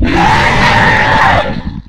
flesh_death_3.ogg